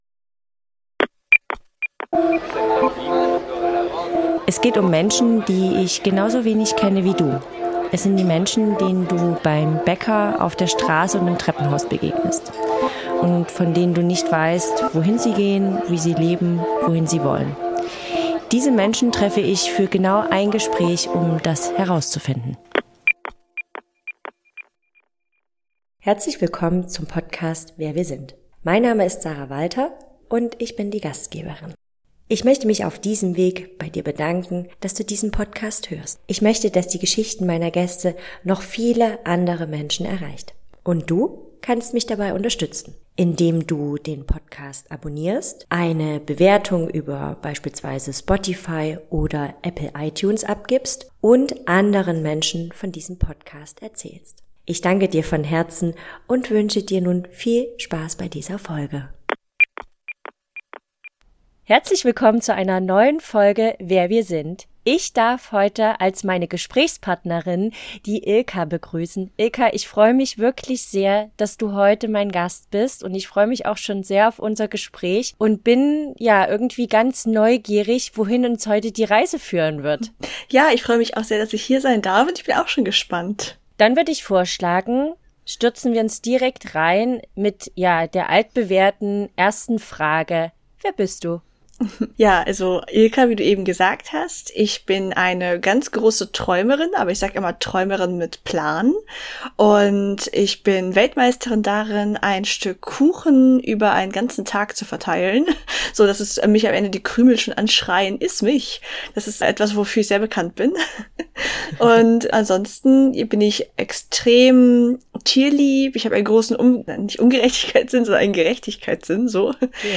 - ein Gespräch. ohne Skript. ohne Labels.